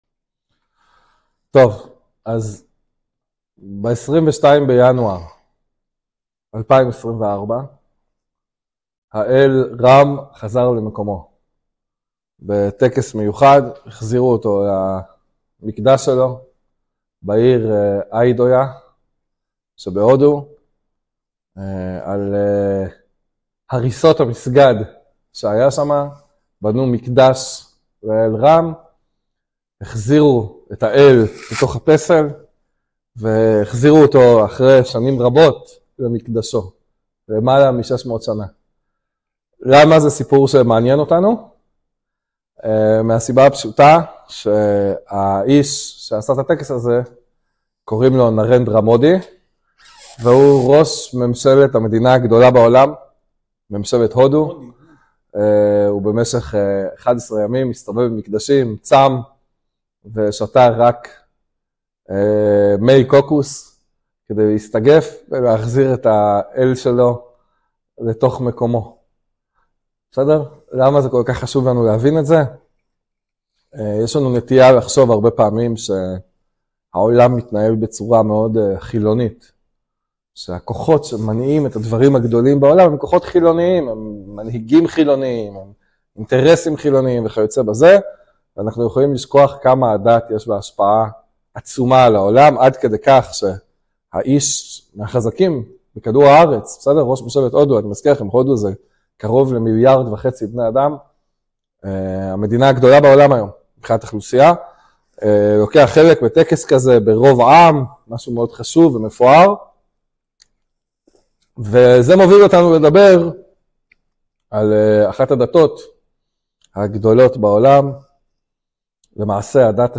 שיעור ראשון על דתות הדהרמה - הינדואיזם